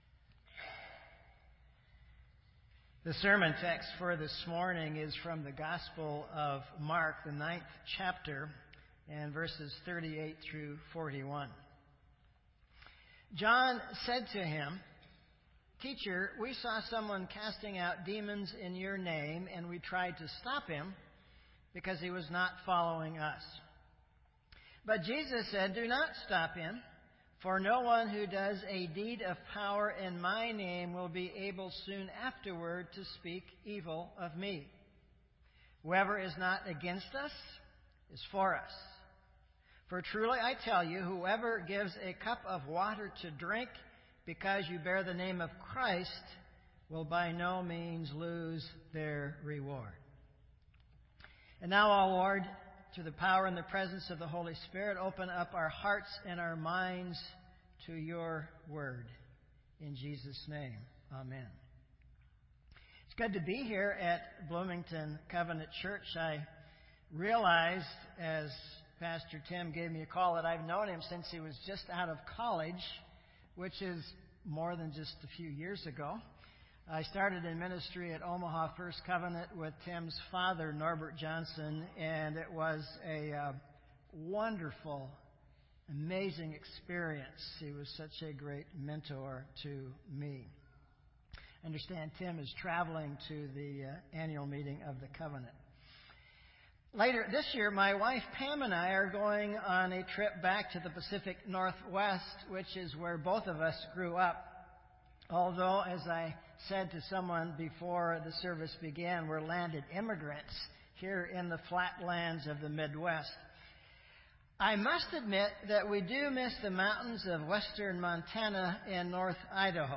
This entry was posted in Sermon Audio on June 26